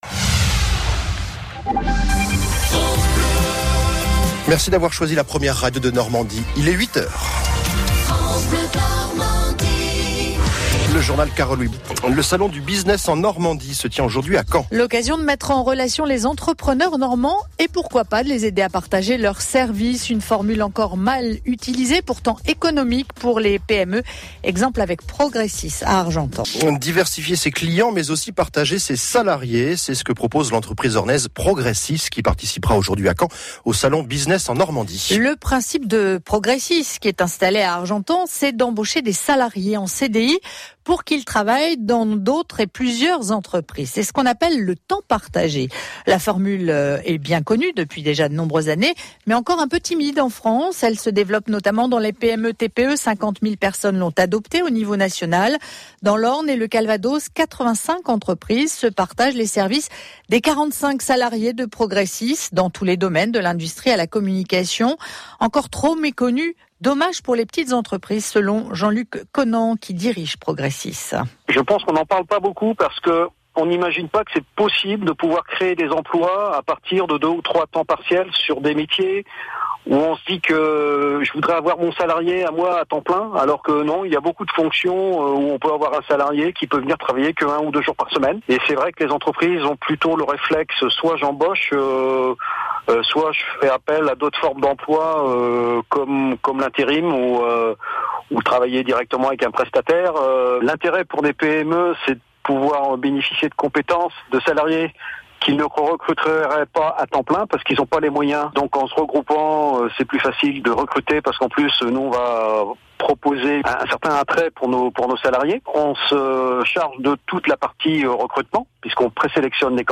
Interviews Progressis sur France Bleu Normandie